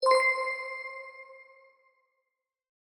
UI_Area_Exit_WhiteGrid.ogg